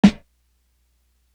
kits/Alchemist/Snares/NB Snare.wav at ts
NB Snare.wav